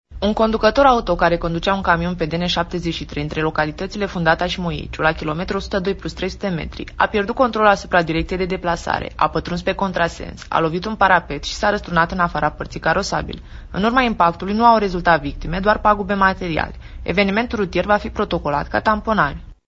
Agent de poliție